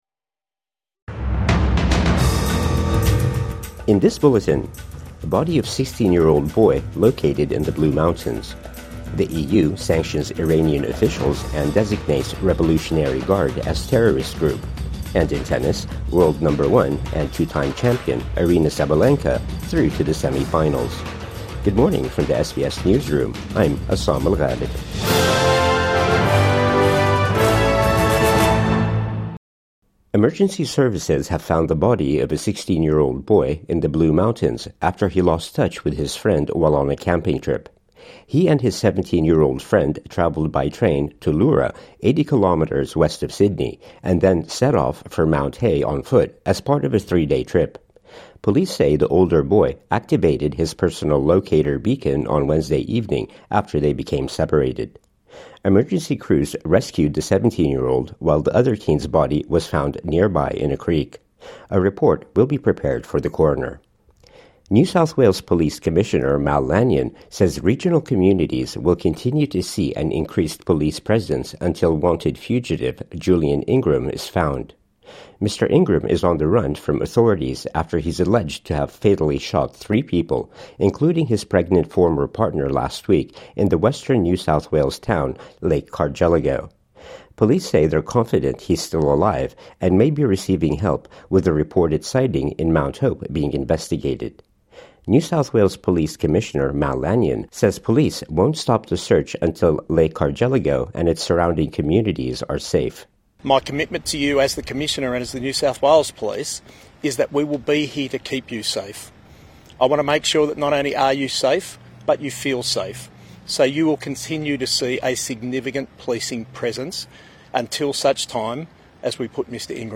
Missing teen’s body found in Blue Mountains | Morning News Bulletin 30 January 2026